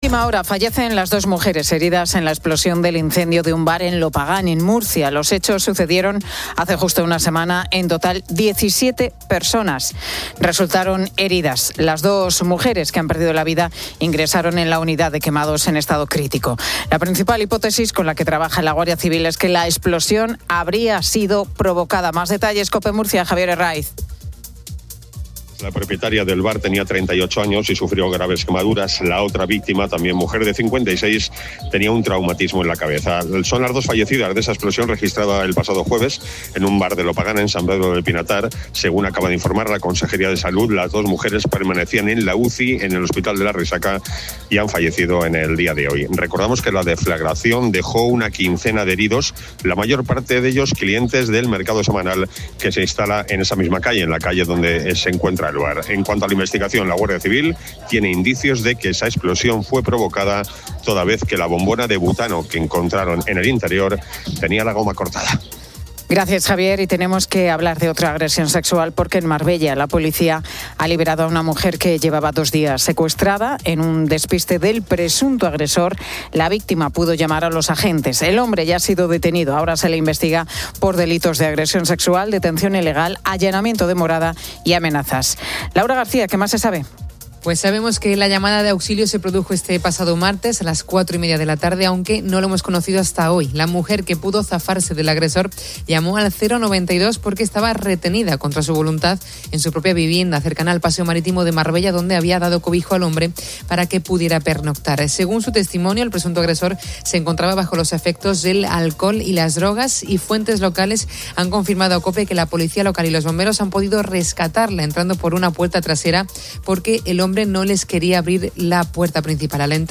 La Tarde 17:00H | 26 JUN 2025 | La Tarde Pilar García Muñiz entrevista a Fito Páez, que presenta su último disco "Novela".